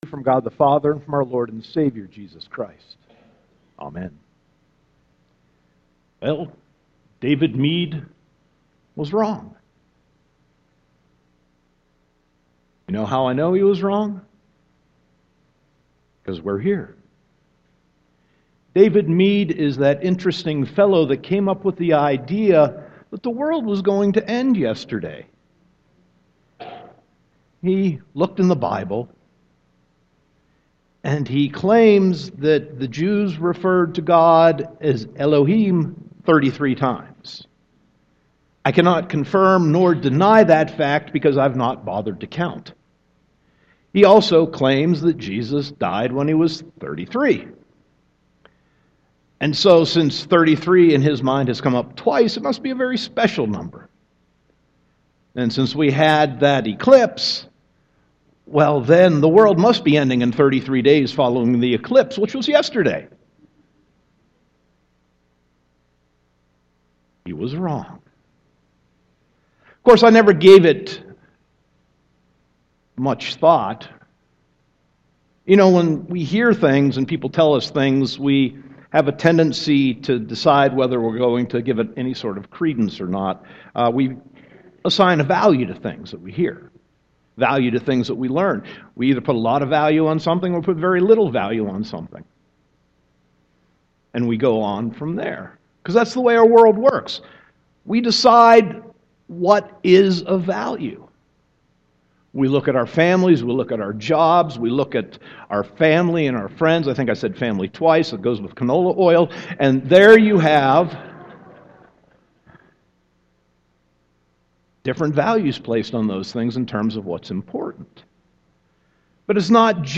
Sermon 9.24.2017